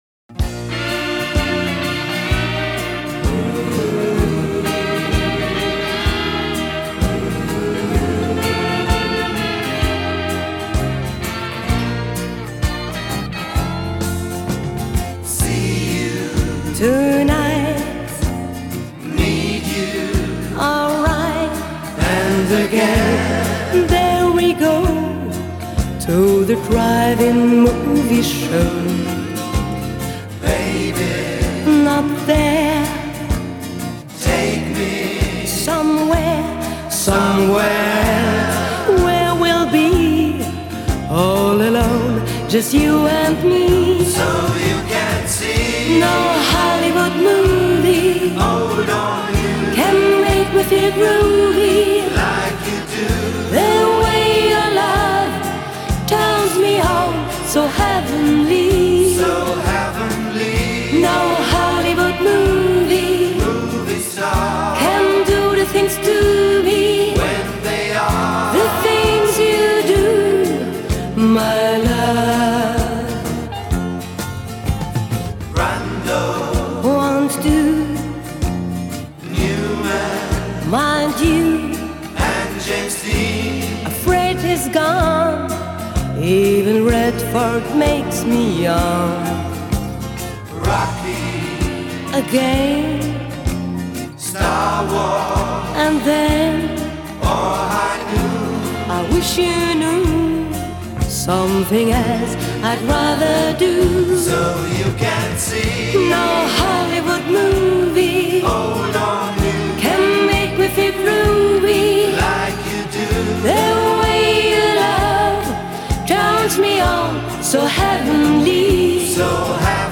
Genre: Pop Rock, Synth-pop, Disco